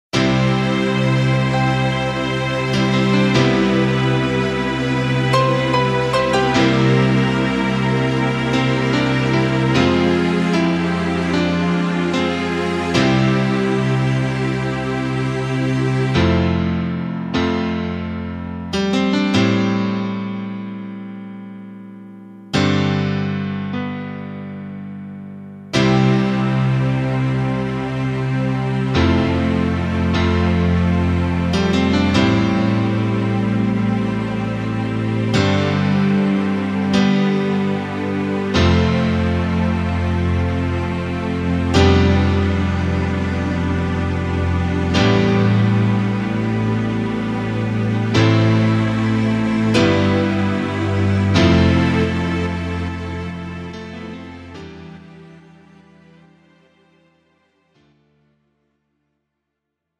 팝송